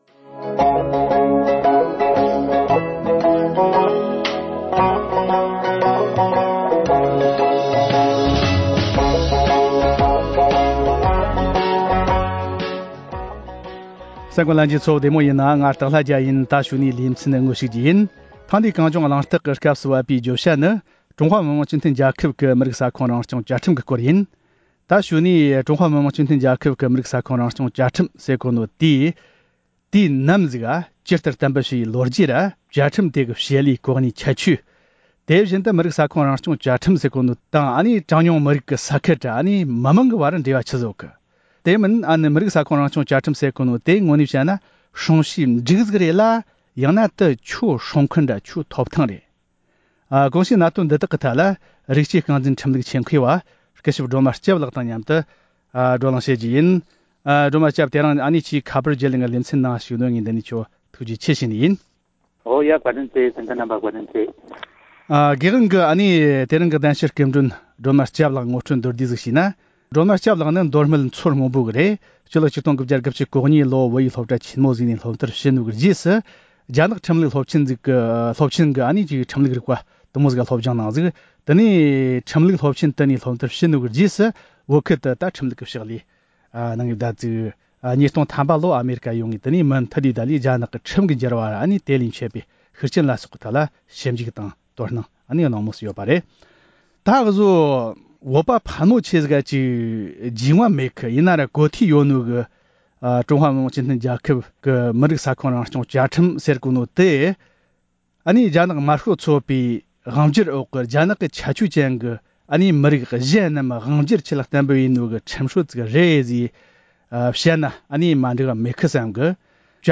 བགྲོ་གླེང་ཞུས་པ།